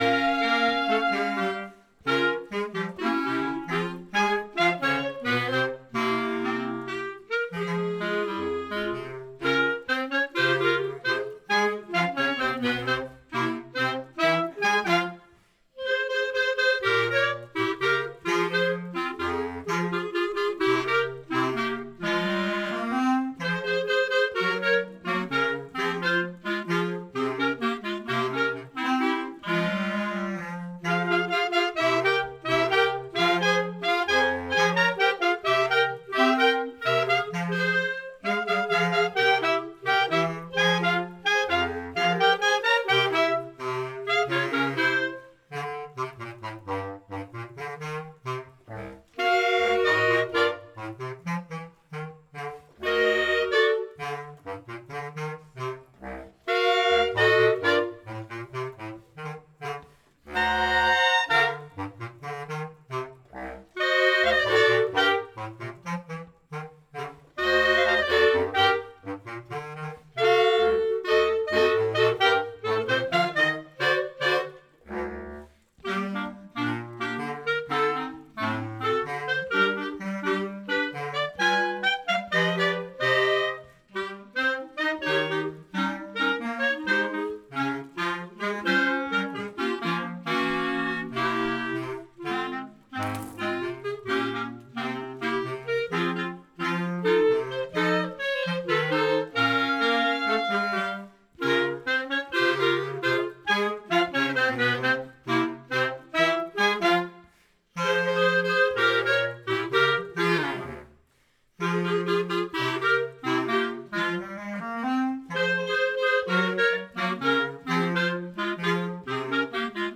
Cela donne notamment l’occasion à l’ensemble de clarinettes de présenter les morceaux que nous avons travaillés…